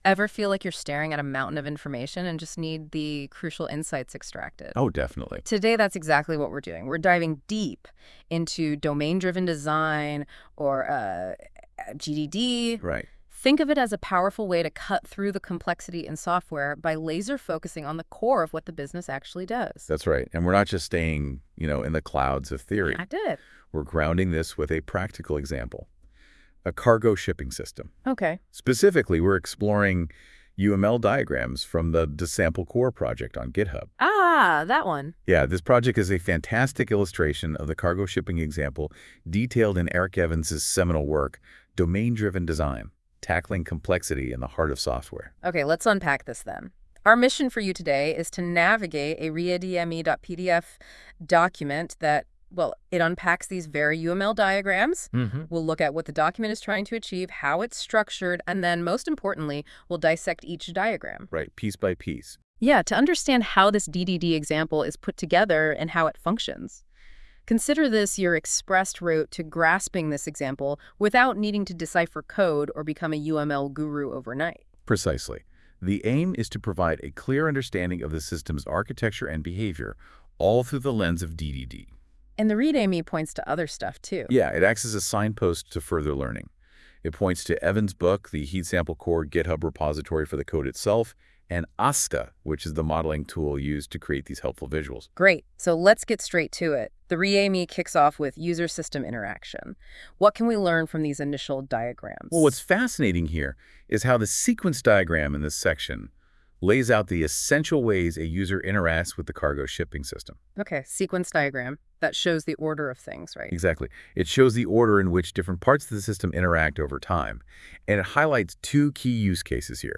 Audio guide for this project – This audio was generated with Google NotebookLM based on the contents of this README.